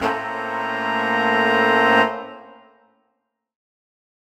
UC_HornSwell_Bmajminb6.wav